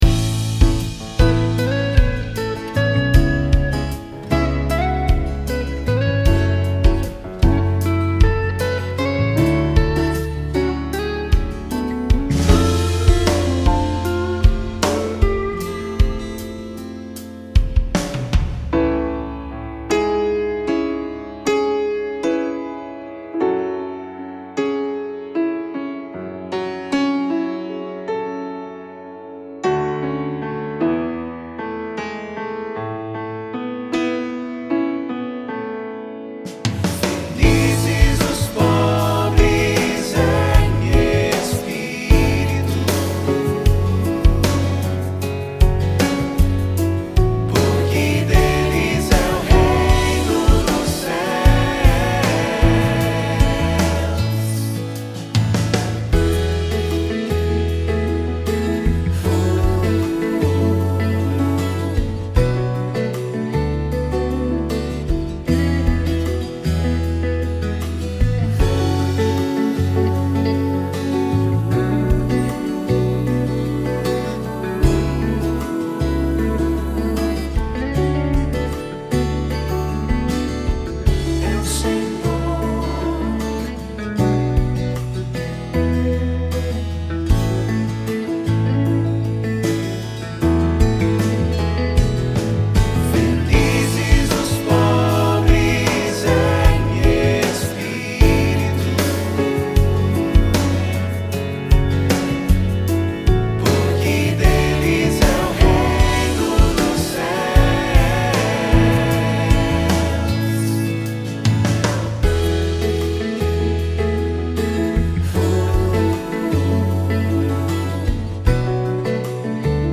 Baixe e Ouça o Playback (música) - SALMO 145 - 29 DE JANEIRO DE 2017
salmo_145_13_playback.mp3